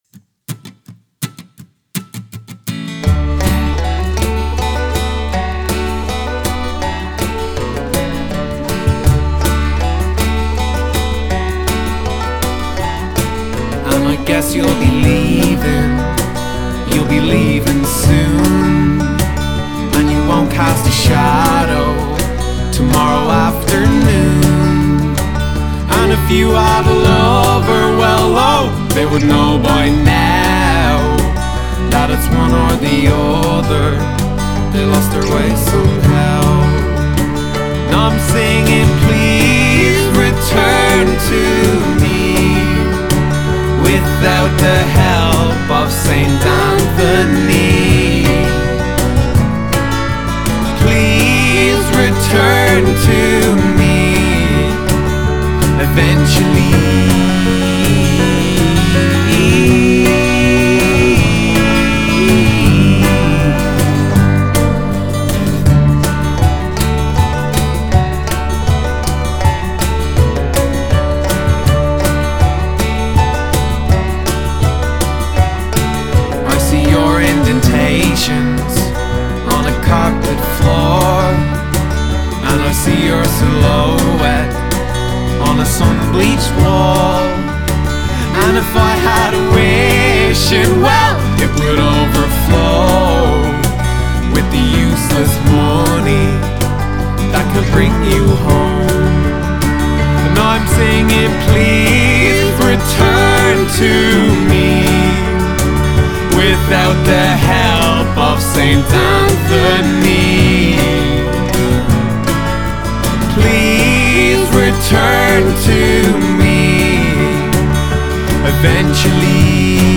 tout en mélodies chaloupées.